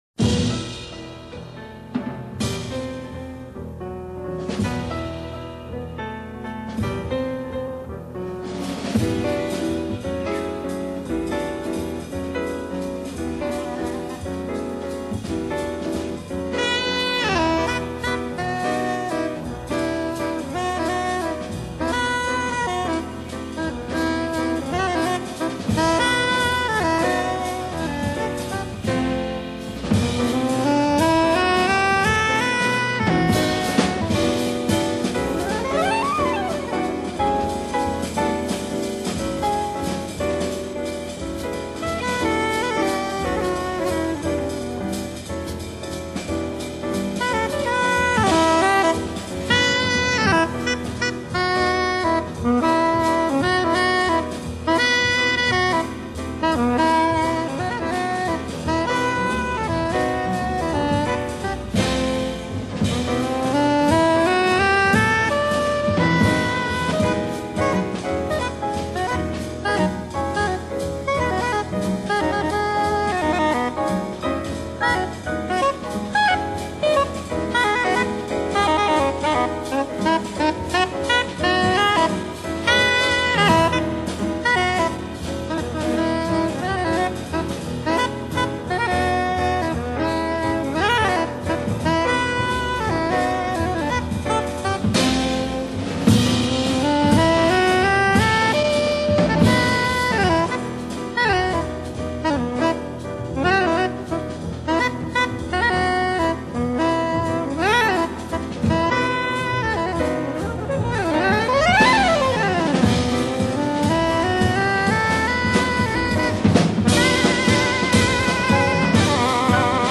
bass clarinet and flute
Piano